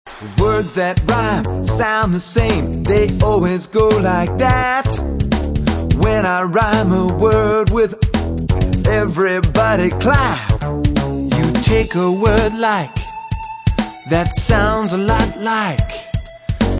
Chants, Cheers, Raps & Poetry Song Lyrics